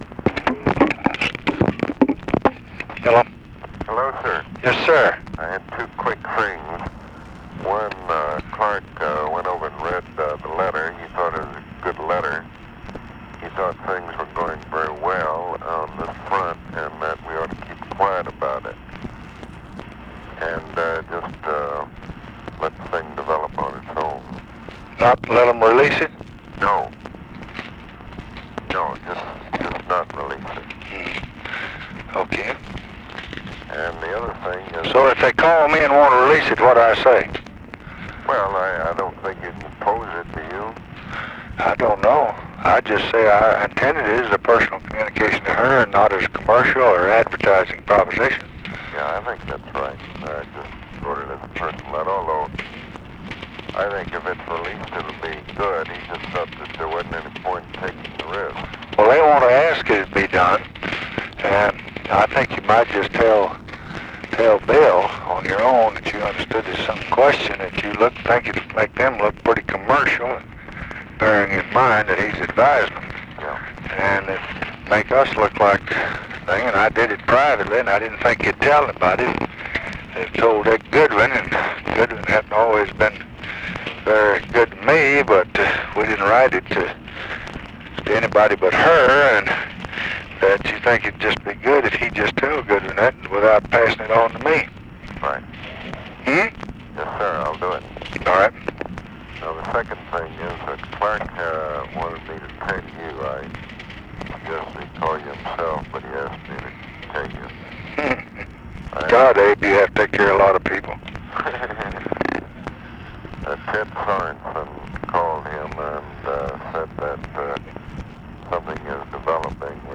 Conversation with ABE FORTAS, December 22, 1966
Secret White House Tapes